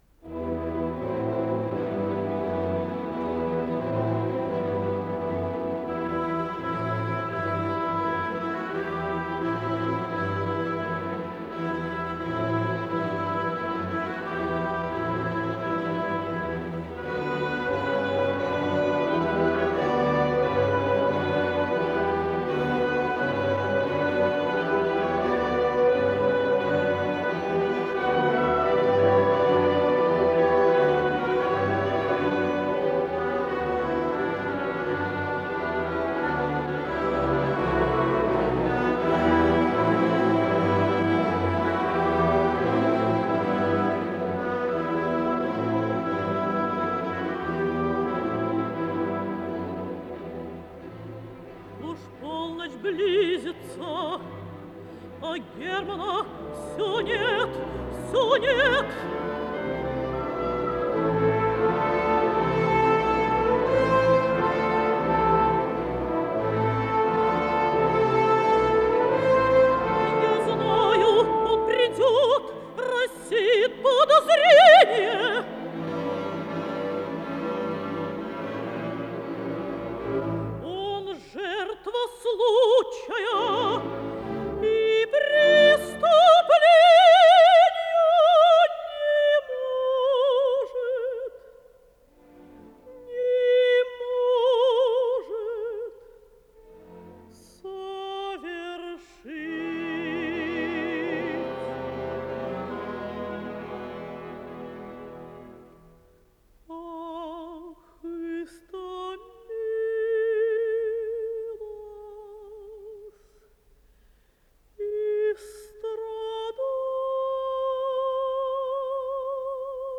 Исполнитель: Солисты, хор и оркестр Государственного академического Большого театра СССР